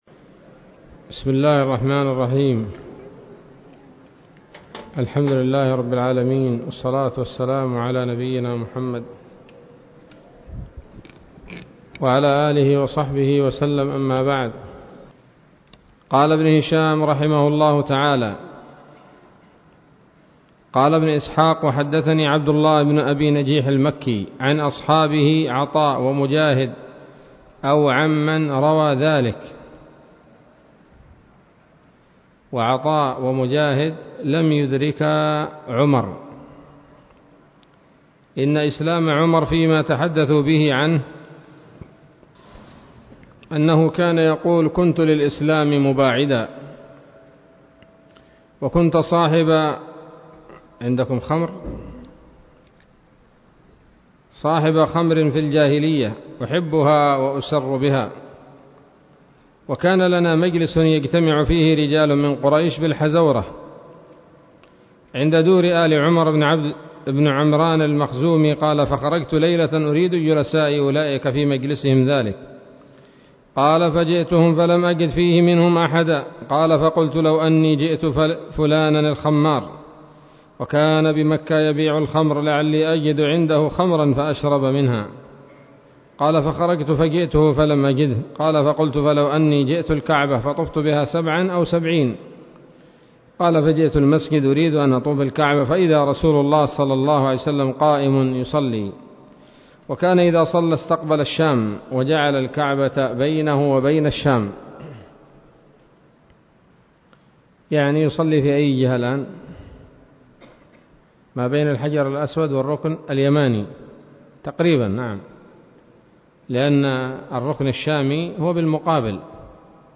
الدرس السابع والثلاثون من التعليق على كتاب السيرة النبوية لابن هشام